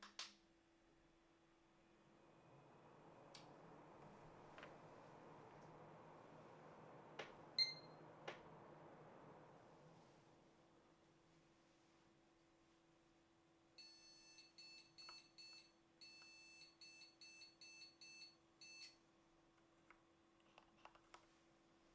Der Beeper zeigt einen Grafikkarten und CPU-Fehler an.
Kurz nach dem GPU-Fehlerpiepen piept es noch ein weiteres Mal.
Piepen.mp3